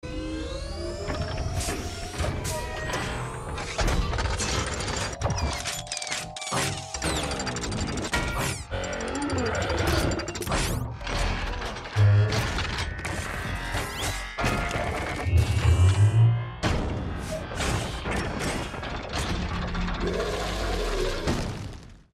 Звуки трансформеров
Звук масштабной трансформации робота в гигантскую машину-убийцу